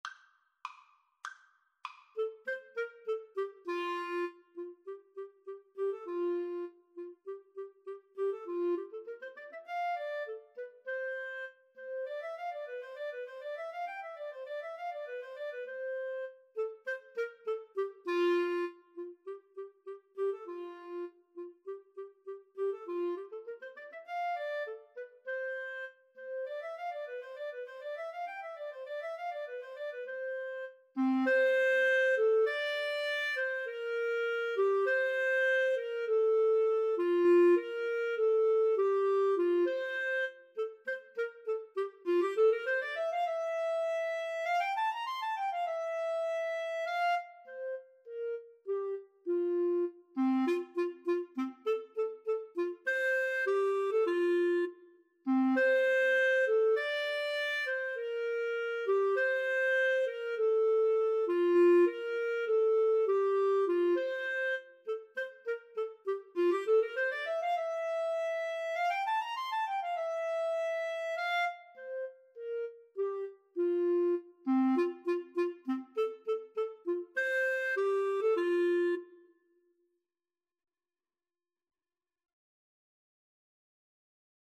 2/4 (View more 2/4 Music)
Classical (View more Classical Clarinet-Viola Duet Music)